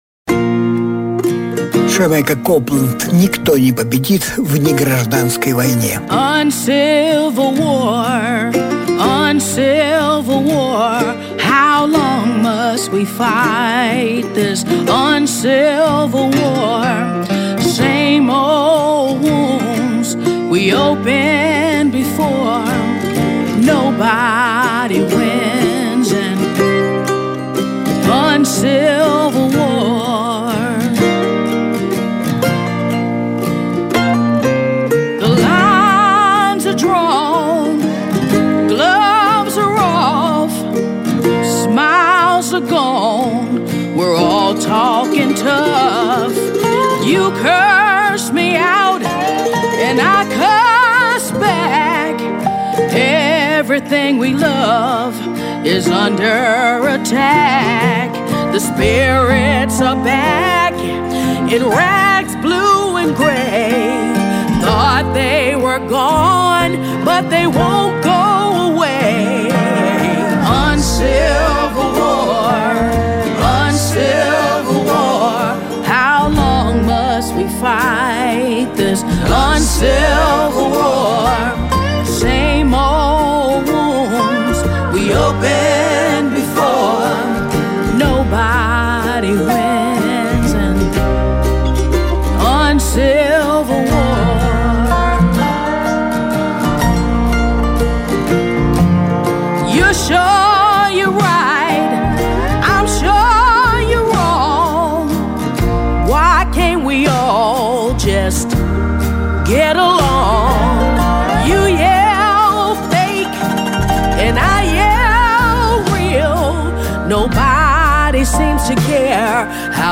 Жанр: Блюзы и блюзики